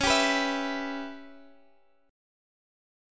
Dbm7b5 Chord
Listen to Dbm7b5 strummed